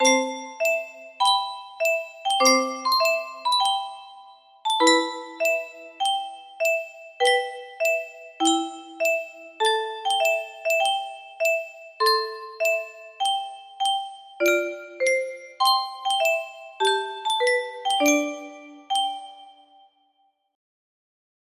versión caja musical